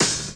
Closed Hats
HIHAT_SWEET.wav